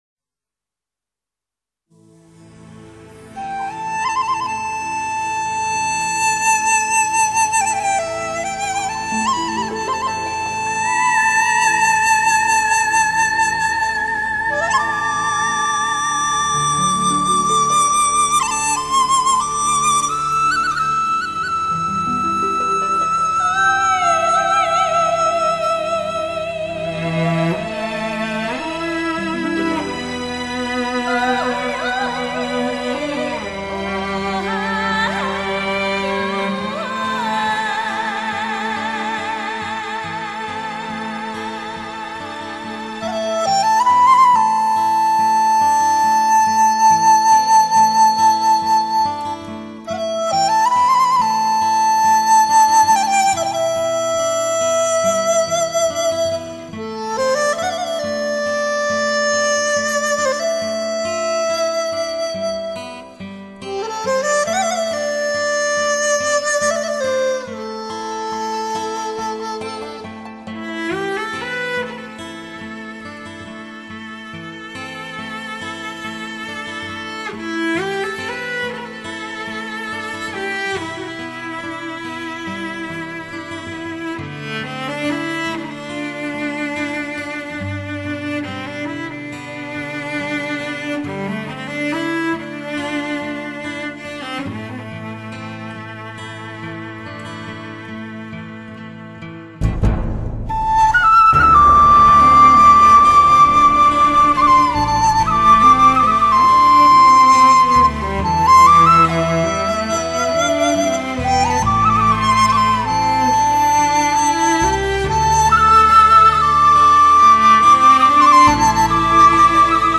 类　　别：DSD
时尚与古典完善结合，民乐也疯狂。
SOUNDEFFECT绝美，低频震撼悠扬抒情，
贴人贴情选曲，乐器本身那明亮珠光，温香软润，